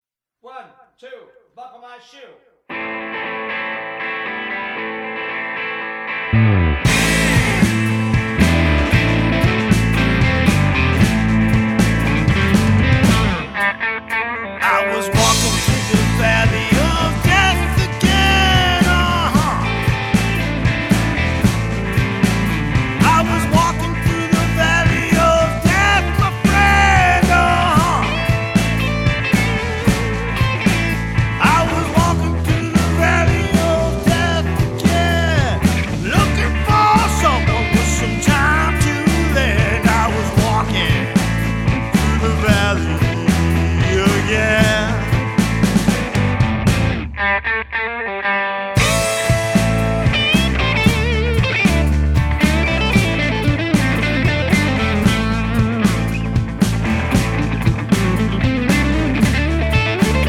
gritty, bluesy, blend of hard rock and roots rock/Americana
whiskey-soaked vocals
The music is straight-up roots rock.
You Can Hear one Minute Duration Audio Song Before Purchase